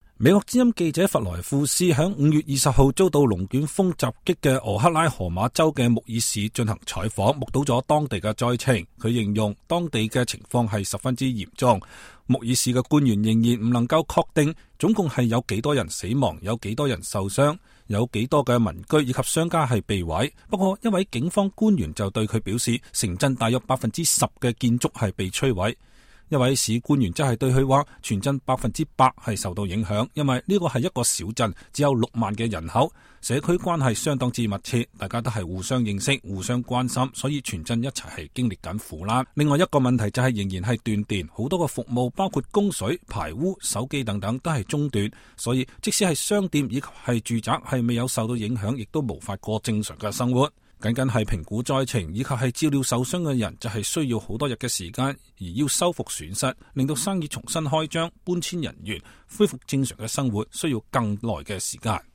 VOA記者現場直擊:遭龍捲風襲擊穆爾市災情嚴重